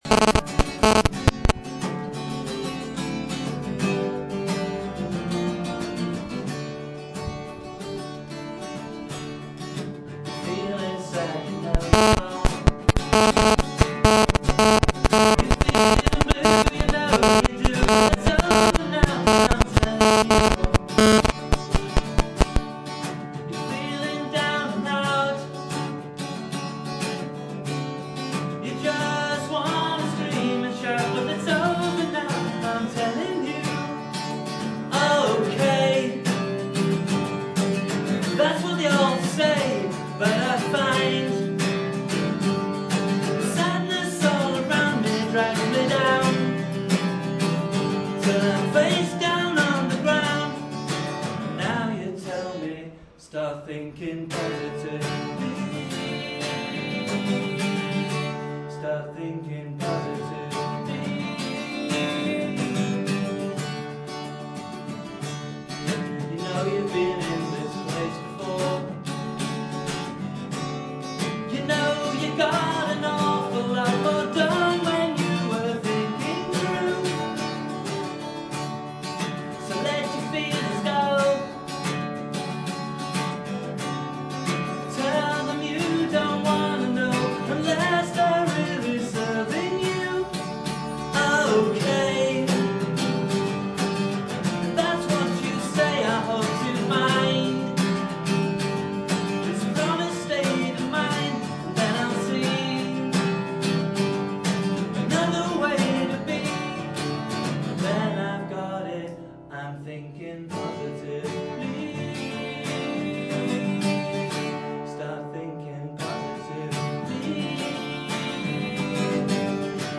Live at London PSA